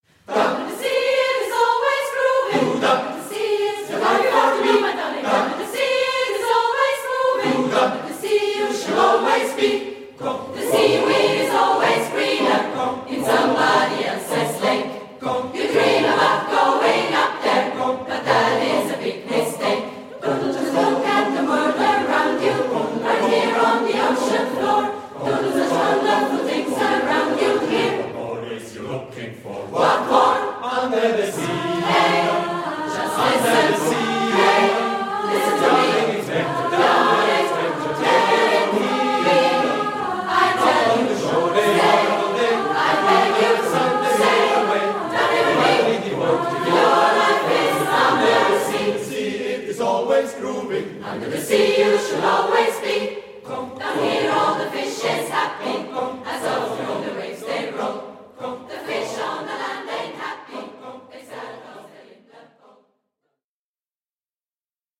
a Cappella
SSAATB